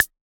Index of /musicradar/retro-drum-machine-samples/Drums Hits/Tape Path B
RDM_TapeB_MT40-Clave.wav